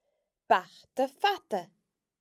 We will cover both the short a and long à forms of the vowel a, with examples to get you used to the sound.